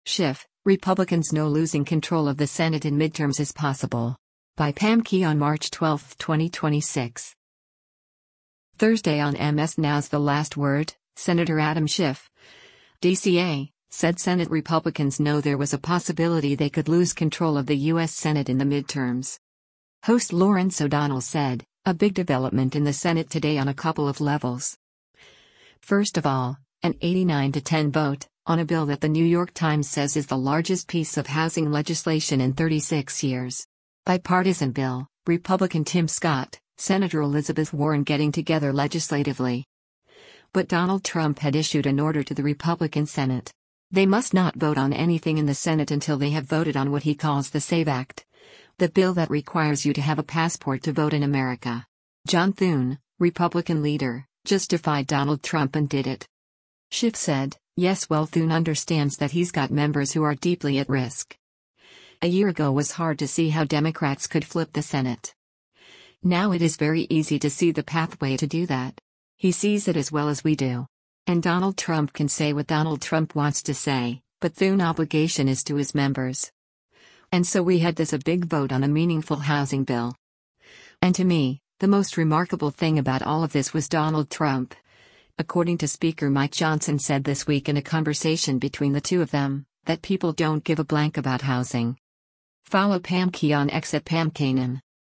Thursday on MS NOW’s “The Last Word,” Sen. Adam Schiff (D-CA) said Senate Republicans know there was a possibility they could lose control of the U.S. Senate in the midterms.